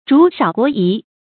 主少國疑 注音： ㄓㄨˇ ㄕㄠˇ ㄍㄨㄛˊ ㄧˊ 讀音讀法： 意思解釋： 君主年幼初立，人心疑懼不安。